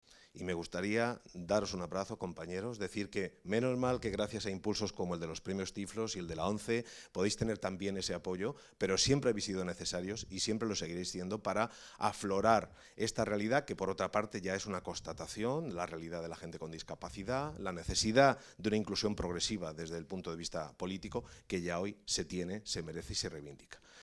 manifestó formato MP3 audio(0,55 MB) el secretario de Estado de Comunicación, Miguel Ángel Oliver, como colofón del acto de entrega de la XXI  edición de los Premios Tiflos de Periodismo Social que concede la ONCE y que tuvo lugar, en el marco de la Facultad de Ciencias de la Información de la Complutense de Madrid, el pasado 9 de mayo.